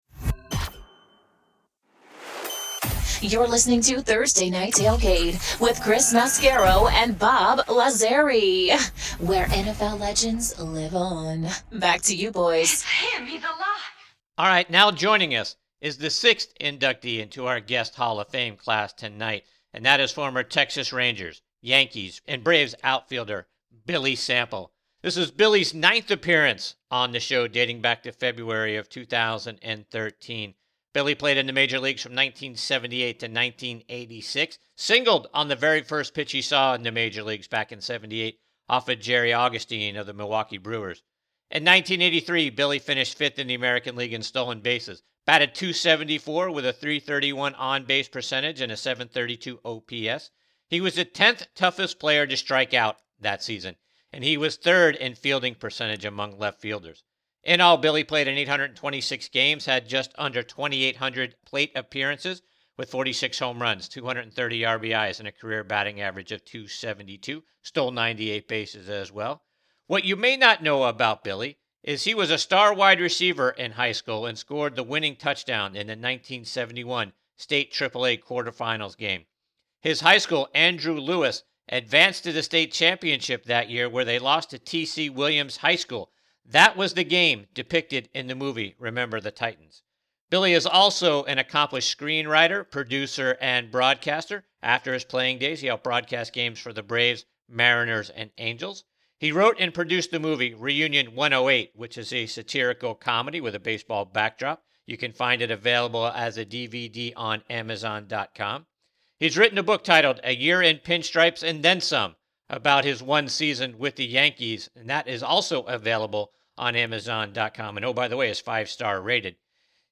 Former Texas Rangers, New York Yankees, & Atlanta Braves OF Billy Sample joins us on this segment of Thursday Night Tailgate NFL Podcast.